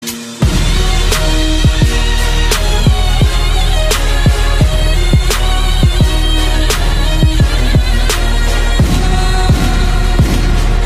plevne-marsi-best-trap-turkish-gazi-osman-pasa-mp3cut.mp3